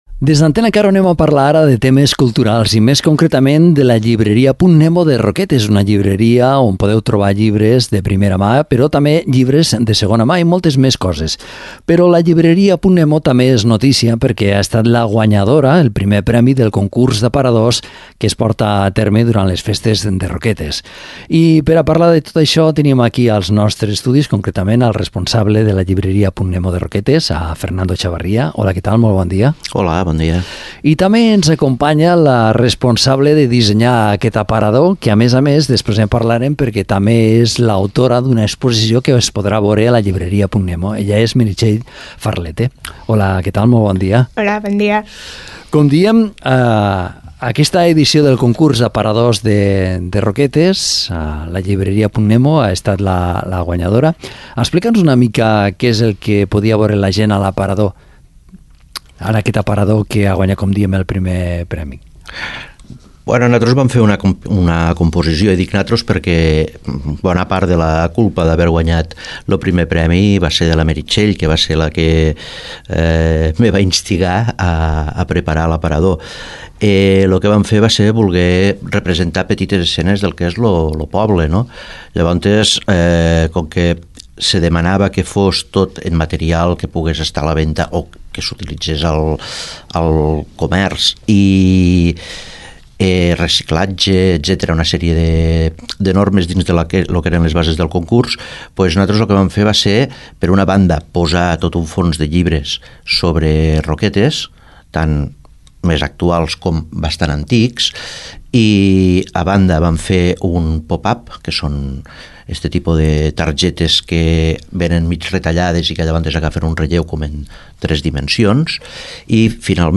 Entrevistem